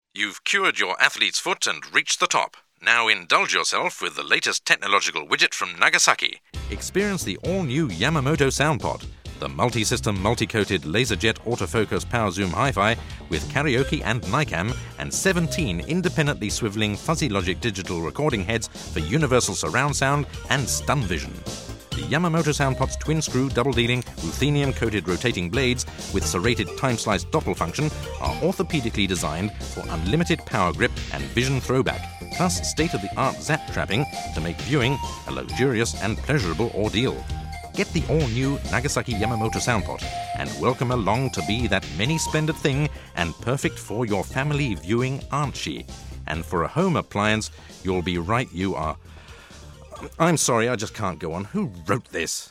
Writer & Performer
sample of his voice